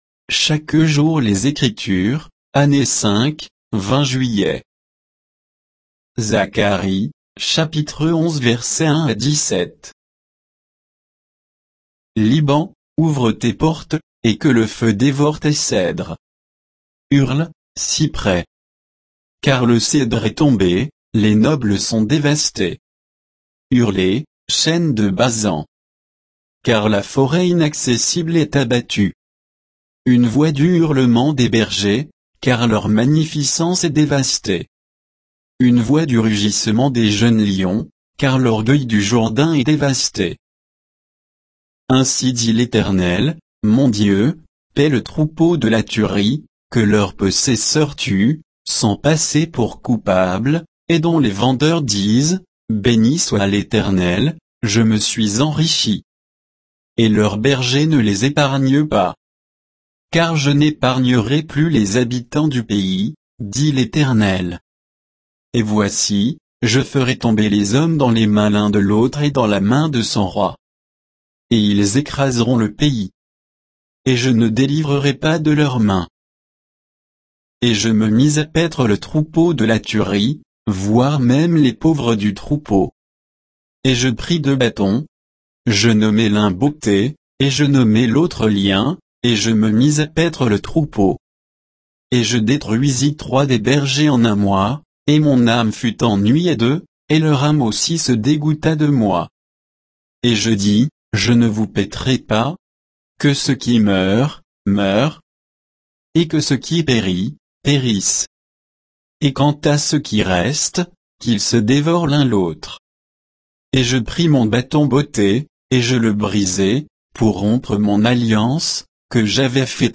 Méditation quoditienne de Chaque jour les Écritures sur Zacharie 11, 1 à 17